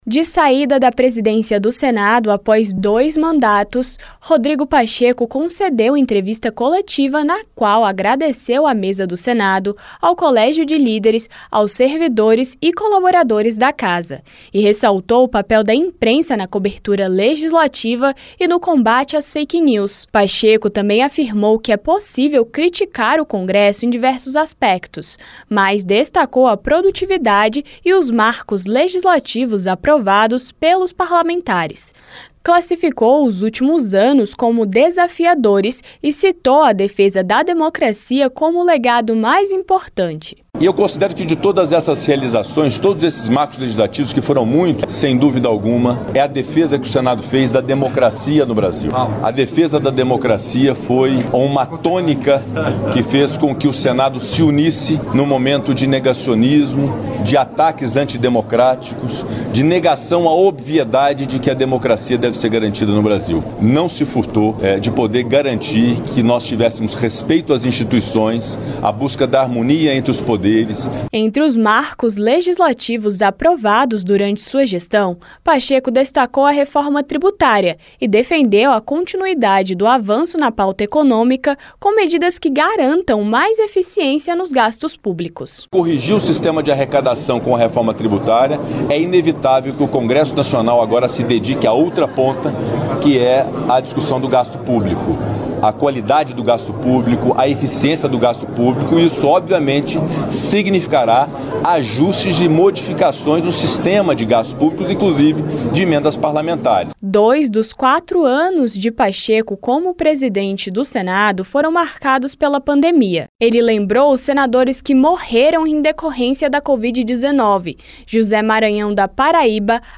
O senador Rodrigo Pacheco (PSD-MG), em sua última entrevista coletiva como presidente do Senado, neste sábado (1º), fez elogios à imprensa, lembrou a atuação da Casa na pandemia e destacou a produtividade e os marcos legislativos aprovados pelos parlamentares, como a reforma tributária. Pacheco citou a defesa da democracia como um legado importante de sua gestão.